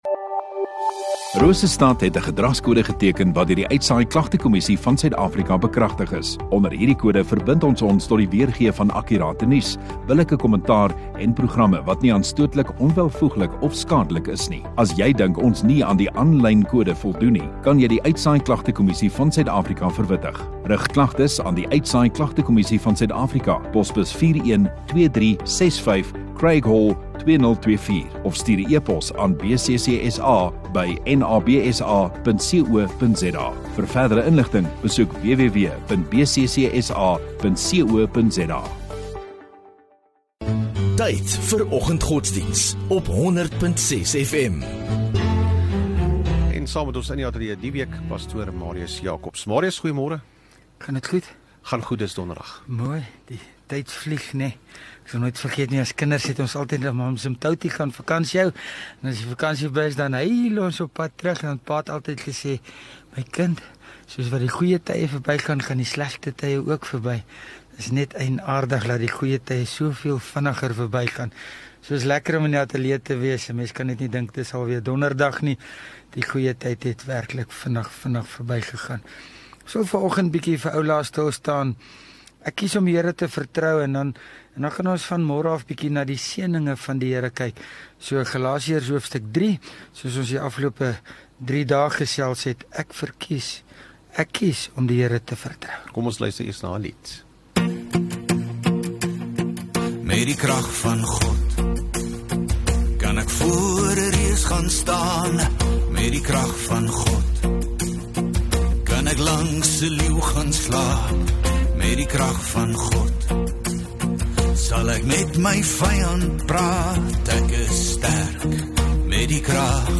31 Oct Donderdag Oggenddiens